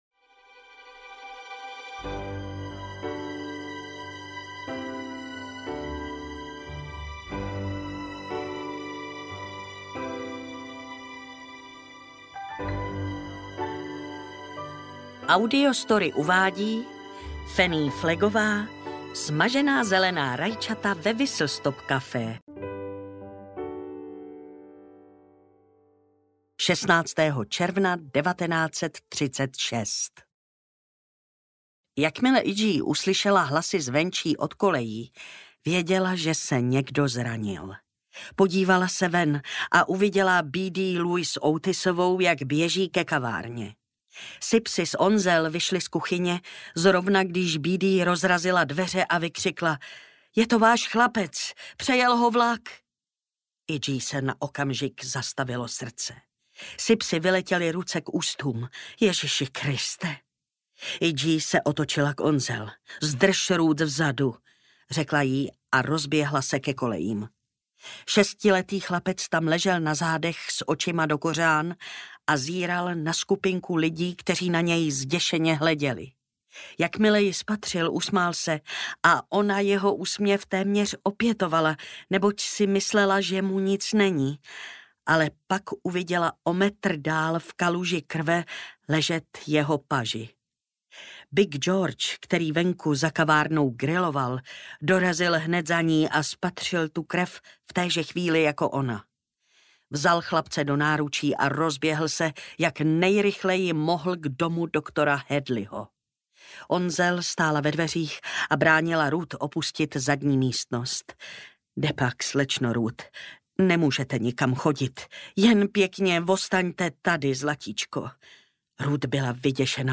Smažená zelená rajčata ve Whistle Stop Cafe audiokniha
Ukázka z knihy
• InterpretMartina Hudečková